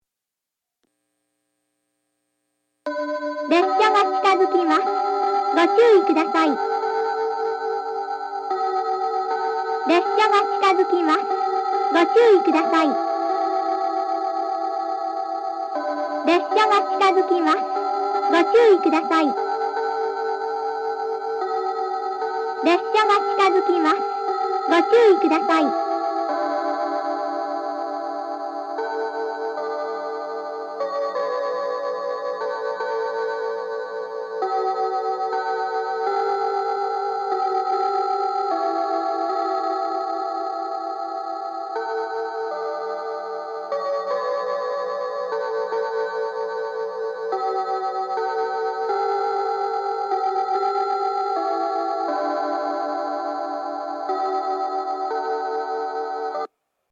上下接近メロディ＋接近放送 かつては接近時に「埴生の宿」が流れ、曲の前半で、九州簡易型の放送が4回入っていました。三角方面では列車の到着と被りますが、熊本方面の列車では被らず収録が可能でした。
収録はソノコラム（ミニ）で行ったものを掲載しています。